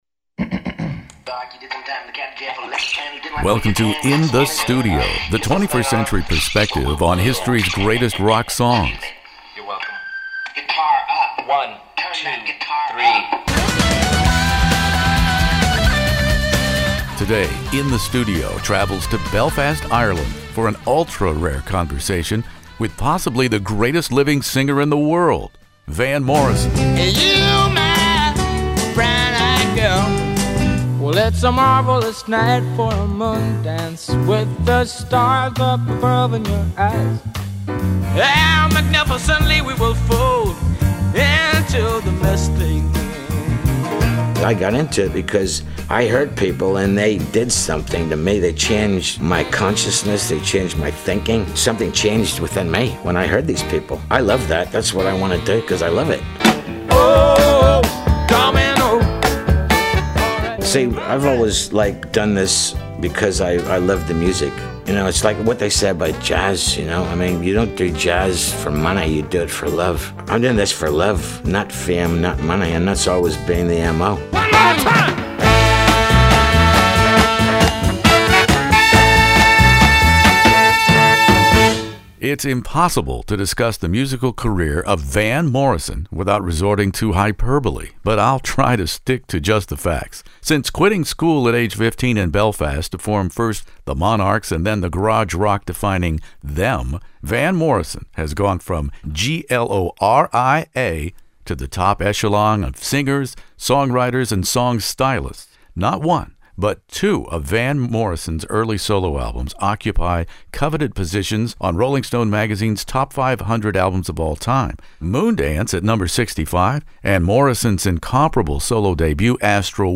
Join us here In the Studio for an ultra-rare, gloves off bare-knuckled conversation with Rock and Roll Hall of Famer Van Morrison.